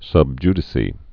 (sŭb jdĭ-sē, sb ydĭ-kā)